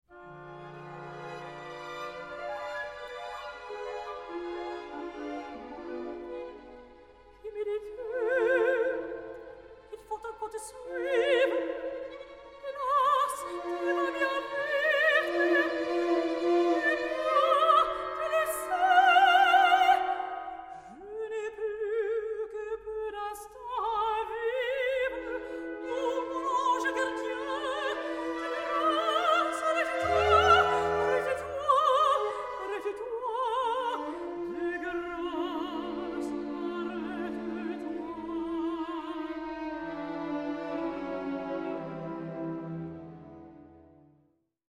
Rare French and Italian Opera Arias
Soprano
Released in stunning Super Audio CD surround sound.